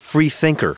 Prononciation du mot freethinker en anglais (fichier audio)
Prononciation du mot : freethinker